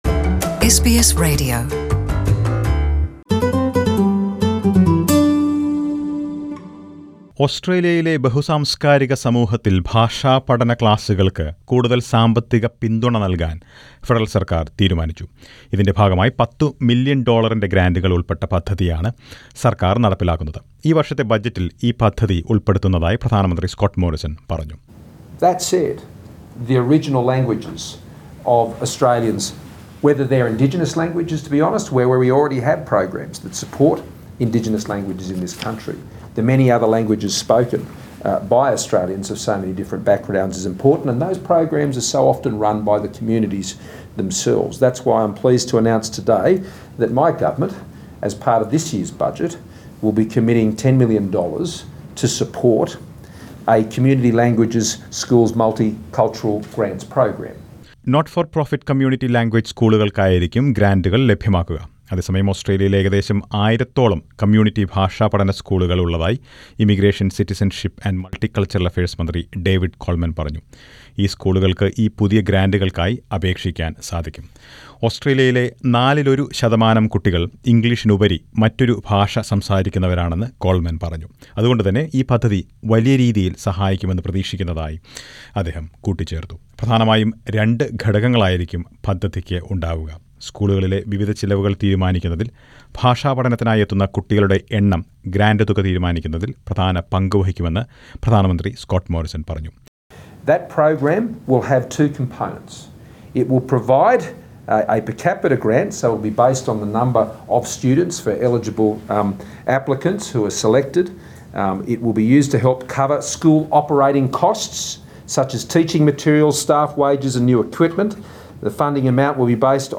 Federal government has announced $10 million dollars in community language school grants to support language learning for the multicultural community in Australia. Listen to a report on this.